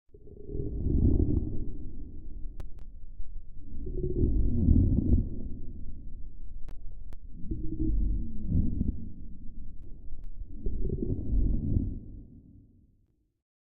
Snoring.ogg